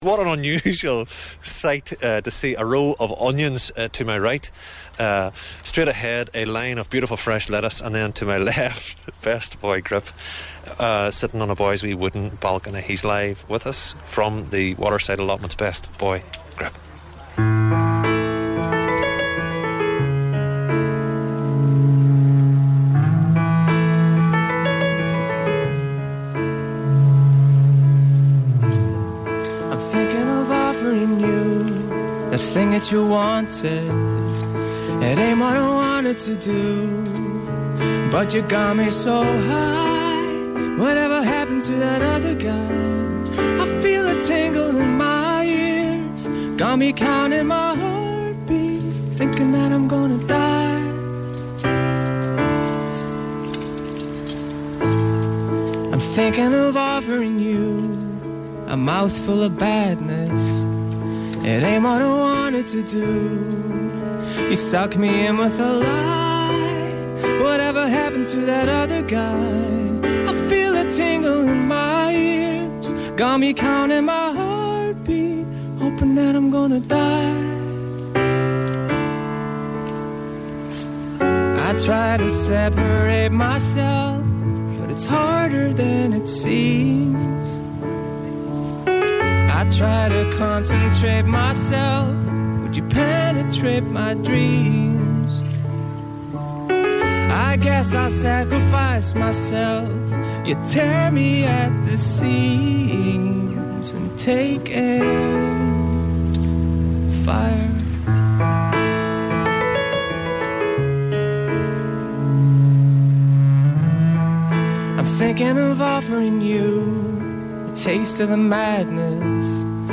allotments live show